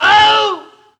Wilhelm Scream Alternative 3
Category 🗣 Voices
death fall falling famous killed legend legendary male sound effect free sound royalty free Voices